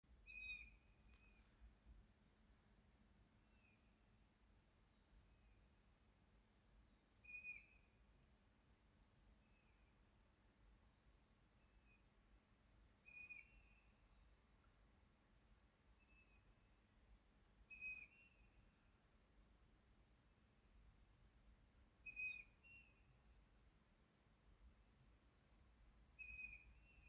Long-eared Owl, Asio otus
Notes2-3 mazuļi sauc naktī